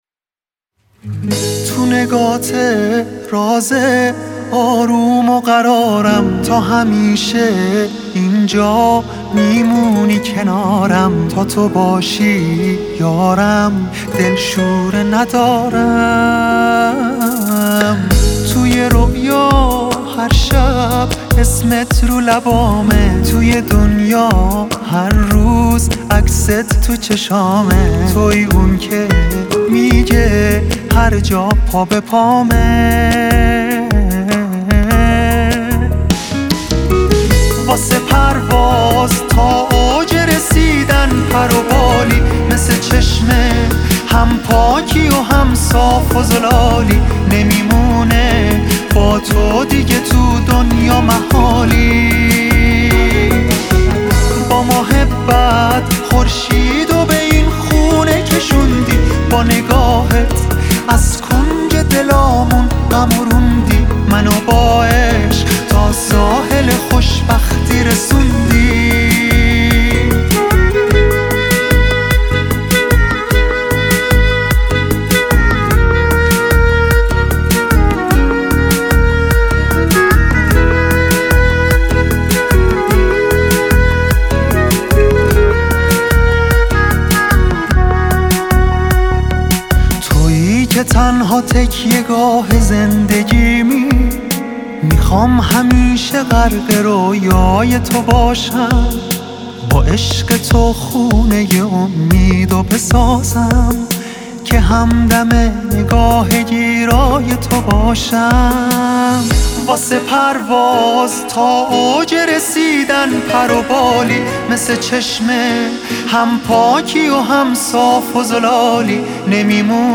1 آخرین مطالب موسیقی موسیقی پاپ